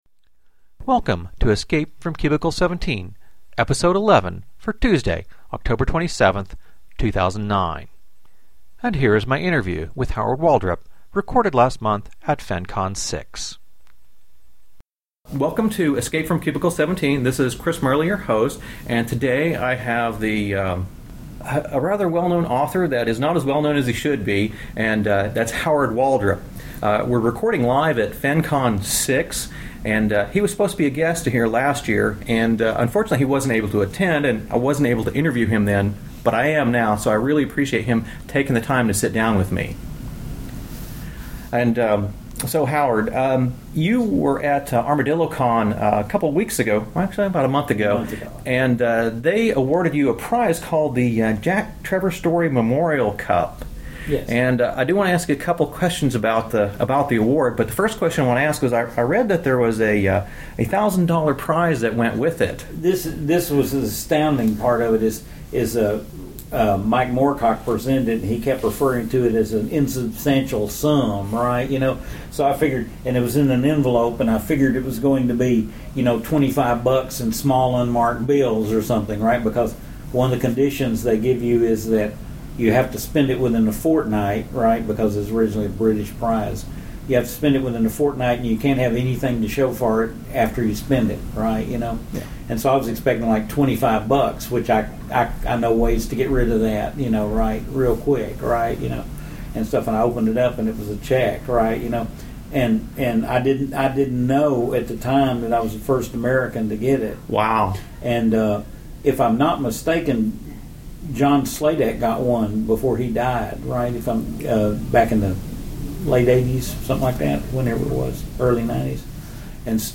This was recorded at FenCon VI in Dallas, TX.